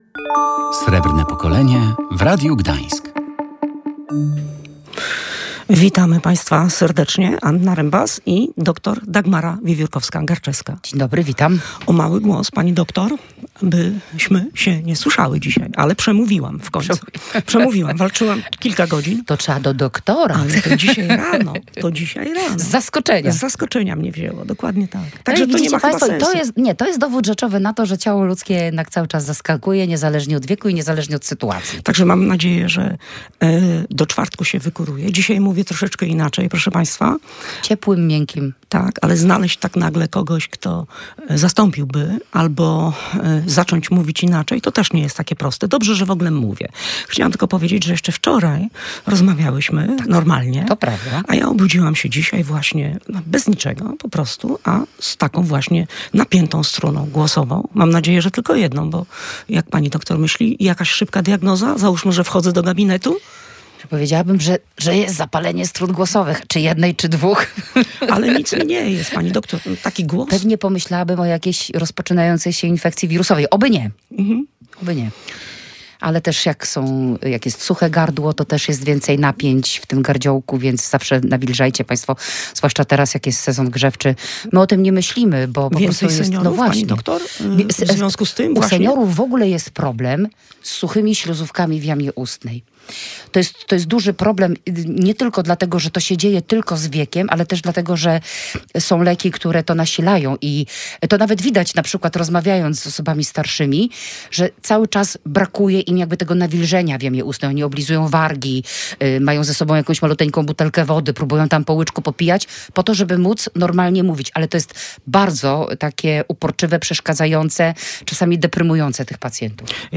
Czy seniorzy muszą całkowicie rezygnować ze spacerów, czy wystarczy, że je ograniczą? W rozmowie poruszono także kwestie dotyczące diety i ubioru, gdy na zewnątrz panuje mróz.